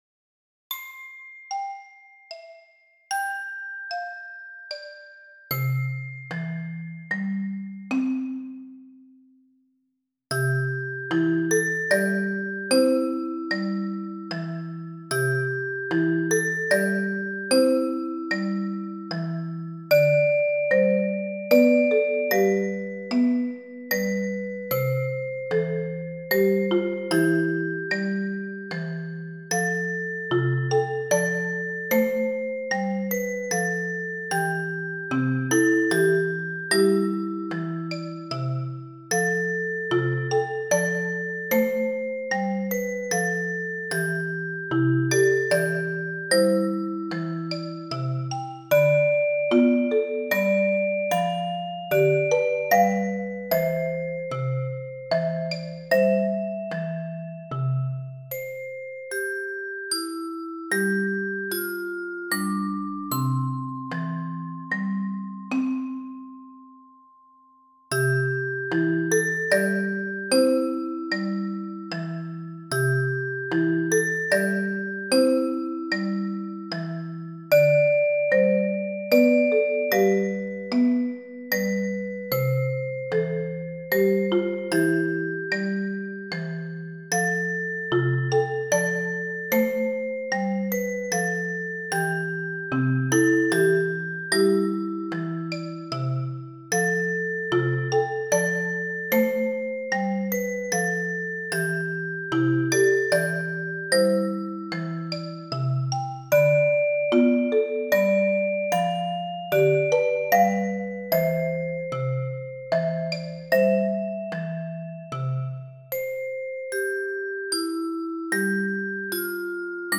Silent Night is a popular Austrian Christmas carol, composed in 1818 in Oberndorf bei Salzburg, Austria by Franz Xaver Gruber.
The arrangement is in C major, 3/4 meter and rather easy.
• Glockenspiel
• Xylophone
• Vibraphone
• Marimba (2players)
Percussion Arrangement Sound file